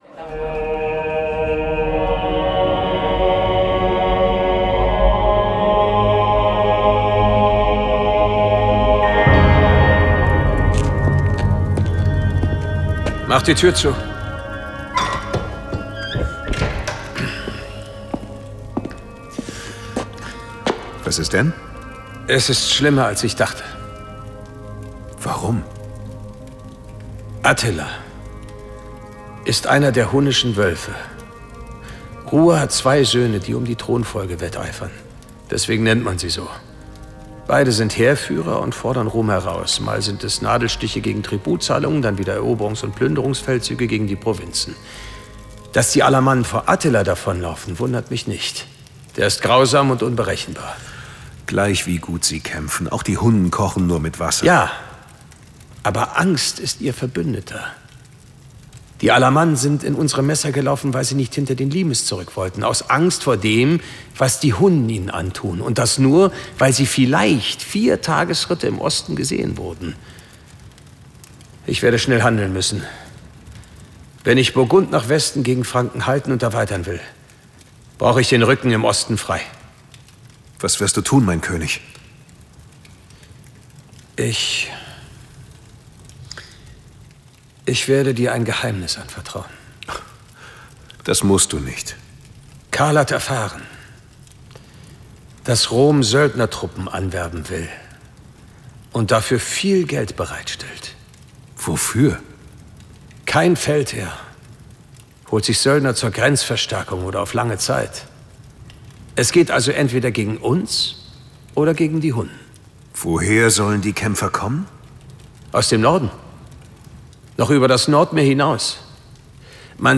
Die Konzentration auf den Ton löst dieses Problem.